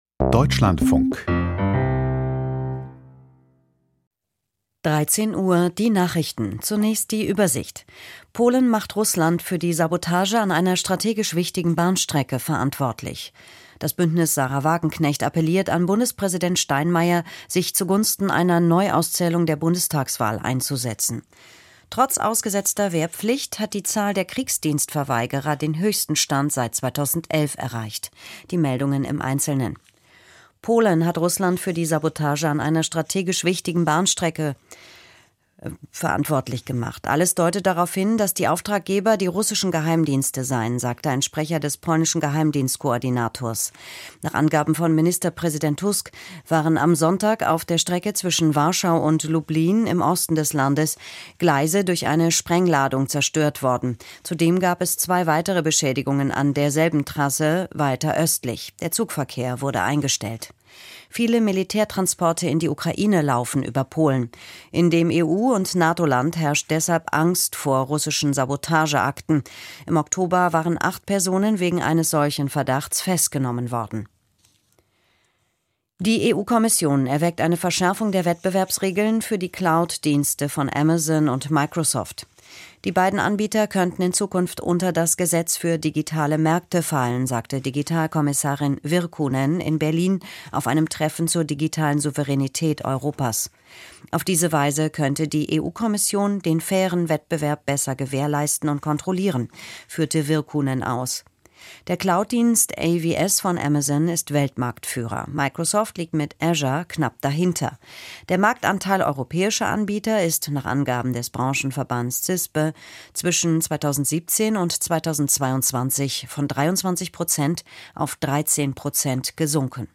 Die Nachrichten vom 18.11.2025, 13:00 Uhr